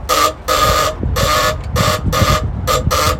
SB-Ford-Horn.mp3